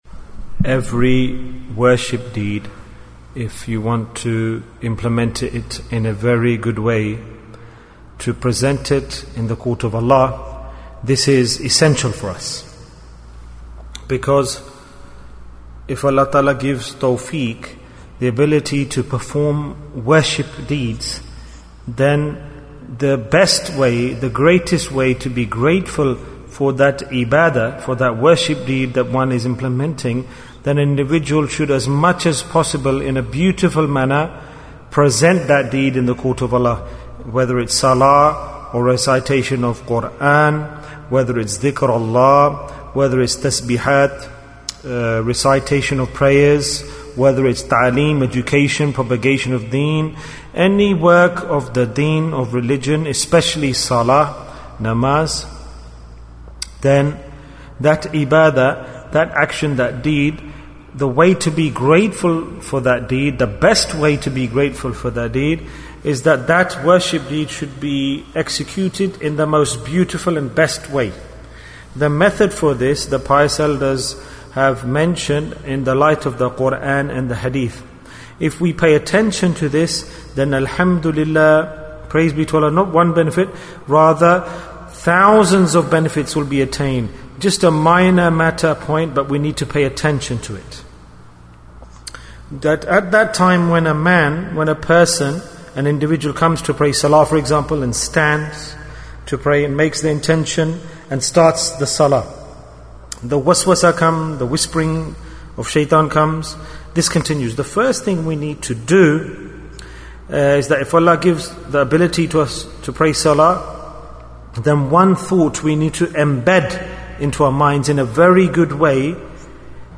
Is it My Last Sajdah? Bayan, 11 minutes17th September, 2019